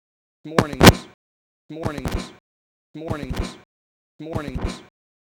Still sounds bad, but at least the glitch is much quieter.
That’s very badly damaged, but I’ve had a go …